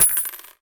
coin6.ogg